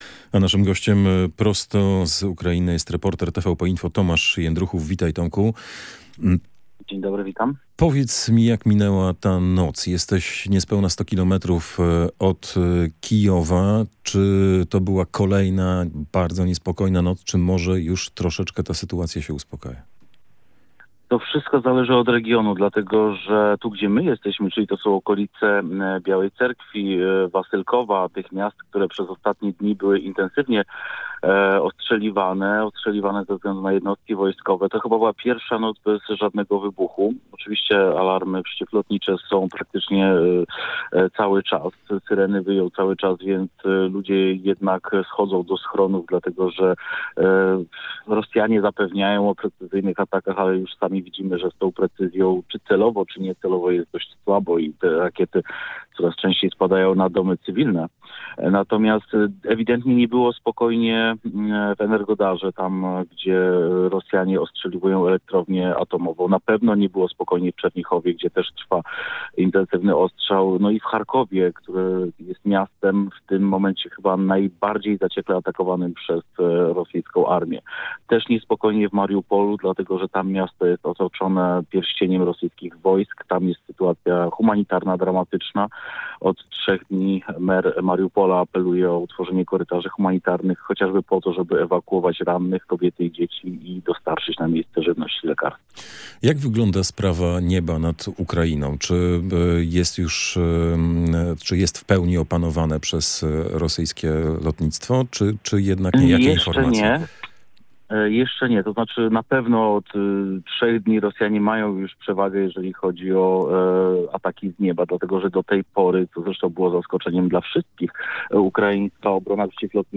miał możliwość połączyć się z nim i zapytać o aktualną sytuację w Ukrainie.